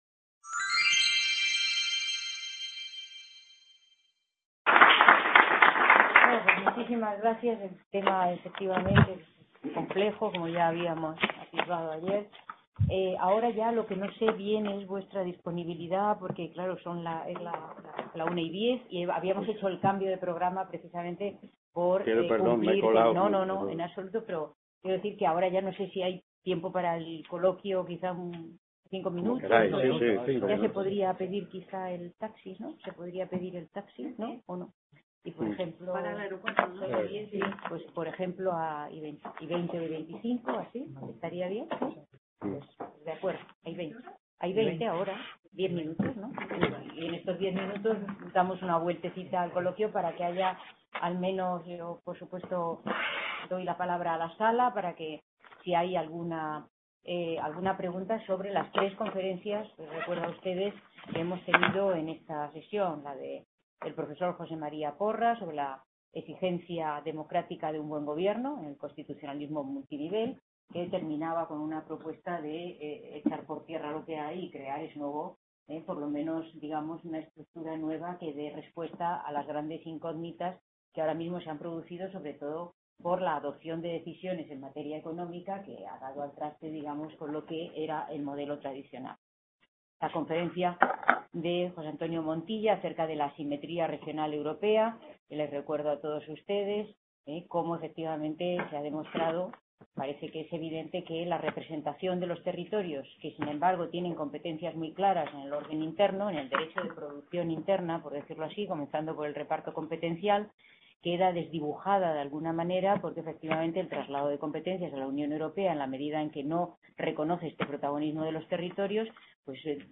Description C.A. Bruselas - IV Congreso Internacional sobre Unión Europea: nuevos problemas, nuevas soluciones.